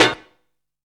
TENOR HIT.wav